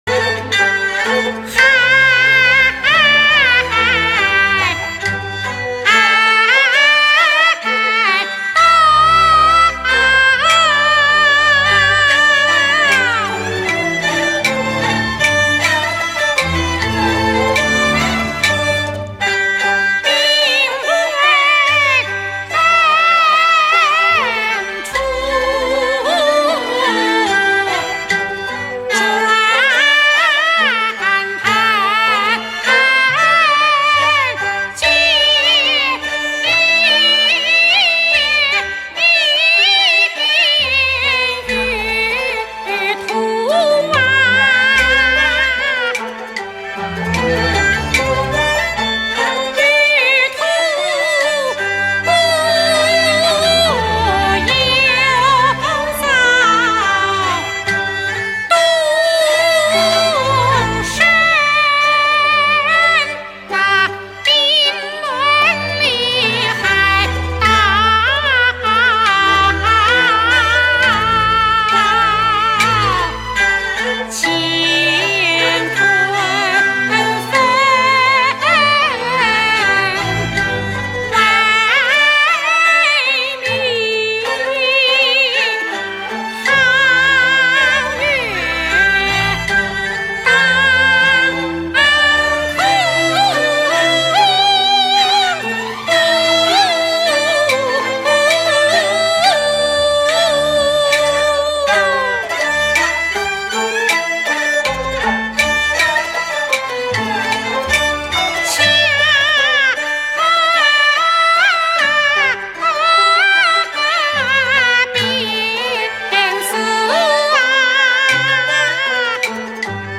A Russian Folk Song